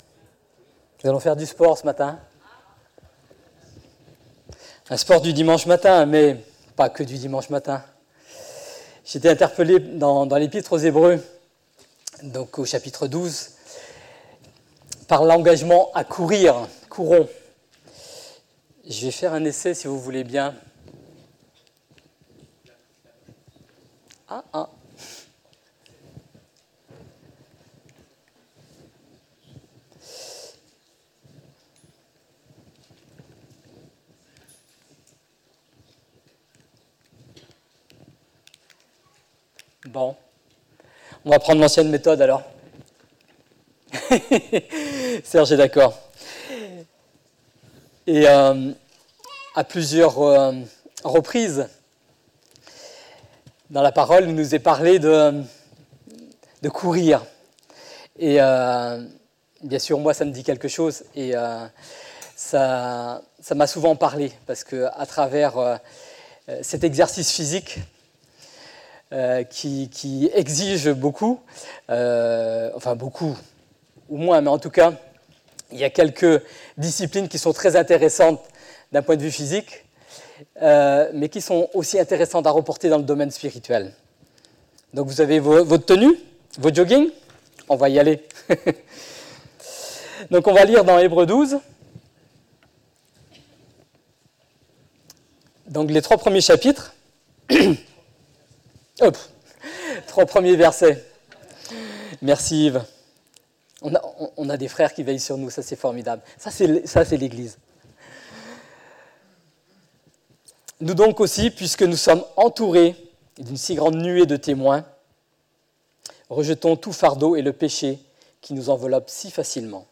"Courons" 15 octobre, 2017 Je te donne le résumé de la prédication de ce matin : Hébreux 12-1 nous encourage à courir : ‘’ courons avec persévérance l'épreuve qui nous est proposée ‘’ et donc comme pour un sportif de nous poser la question de ce qui va nous aider à accomplir la course de notre vie sur terre.